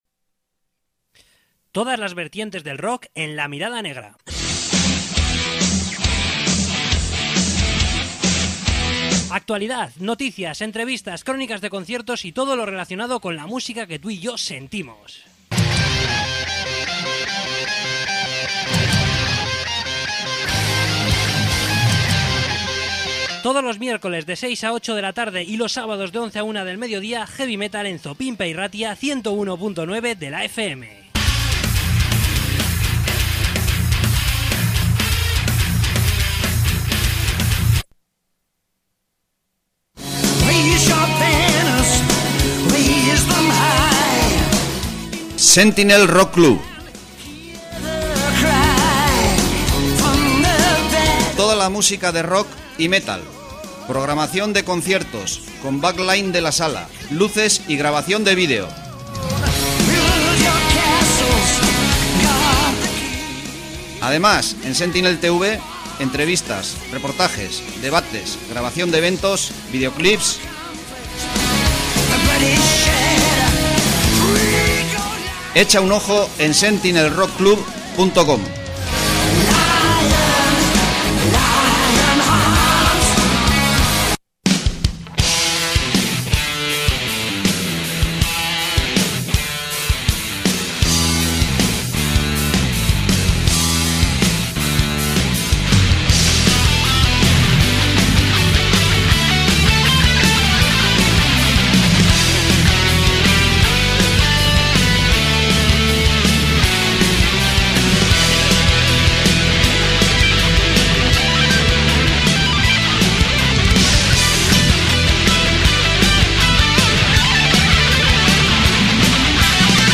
Entrevista con Desakato